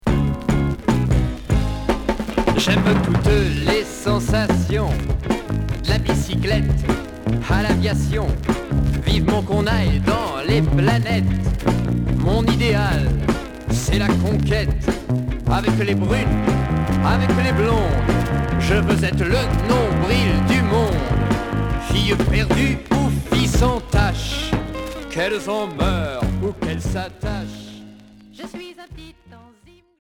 Chanteur 60's